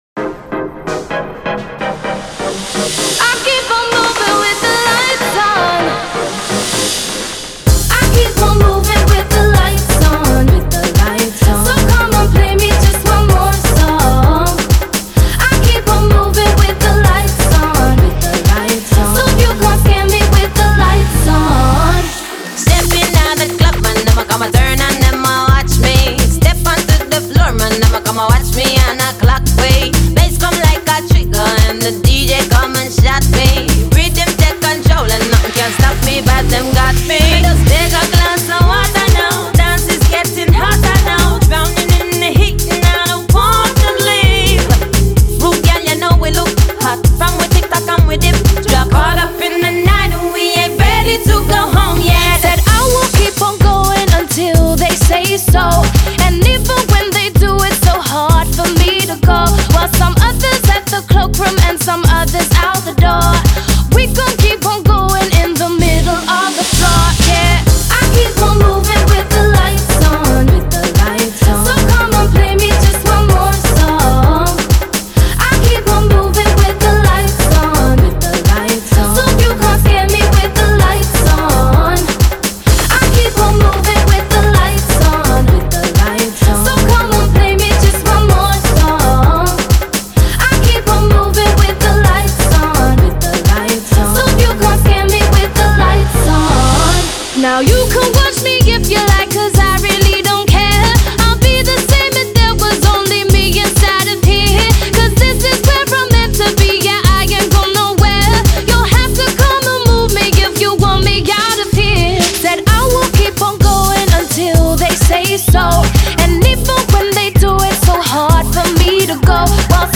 Genre: Funky, Dance